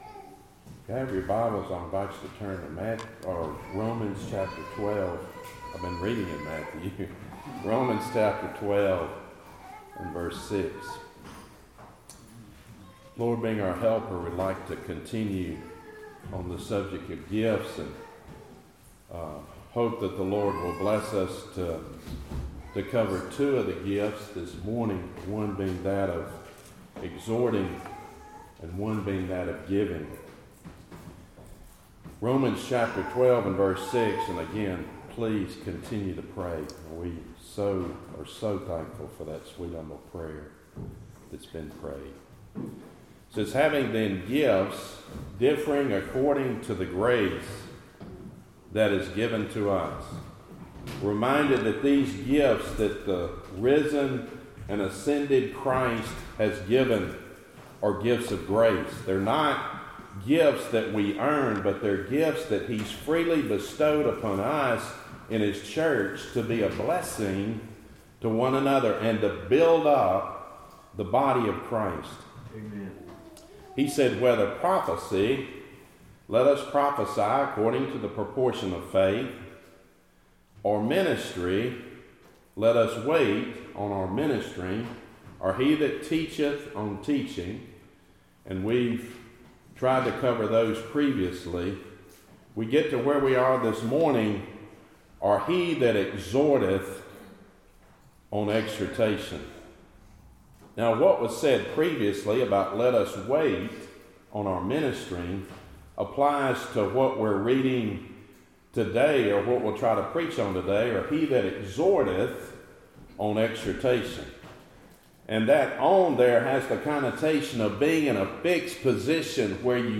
Series: Gifts in the Church Topic: Sermons